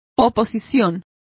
Complete with pronunciation of the translation of opposition.